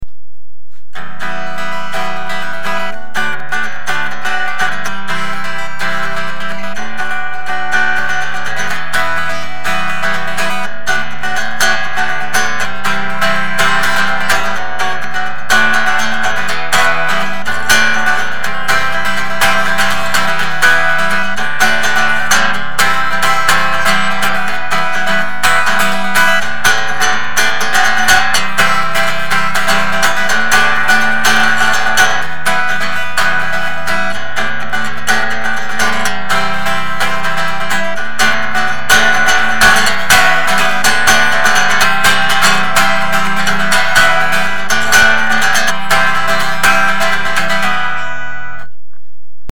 gratte de voyage
pas mal de défaut au niveau de la fileterie mais un son acceptable et finalement assez puissant au regard de la taille de la caisse. je l’ai toujours et elle sonne toujours bien.